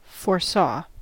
Ääntäminen
Ääntäminen US Tuntematon aksentti: IPA : /foɹˈsɑʊ/ Haettu sana löytyi näillä lähdekielillä: englanti Foresaw on sanan foresee imperfekti.